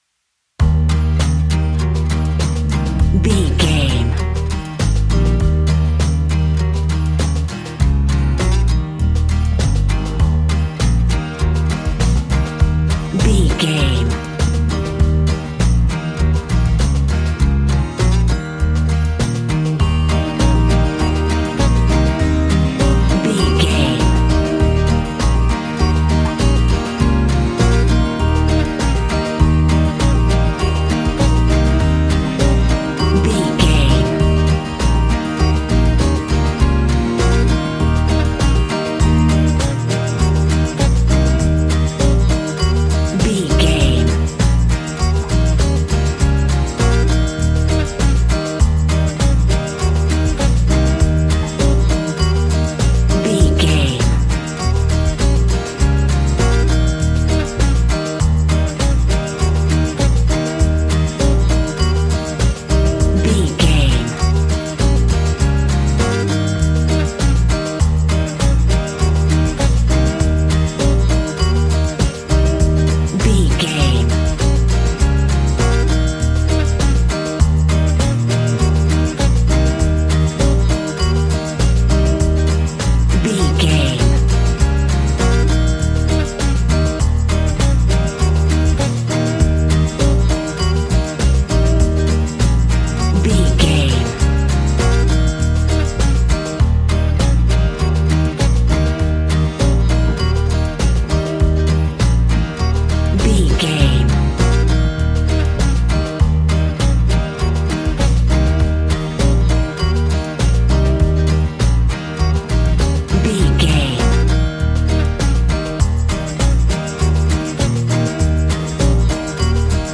Country and folk music for the farmyard.
Ionian/Major
E♭
Fast
fun
bouncy
double bass
drums
acoustic guitar